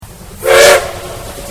汽笛（蒸気機関車の音）
秩父鉄道の浦山口-武州中川間で録音。短い汽笛です。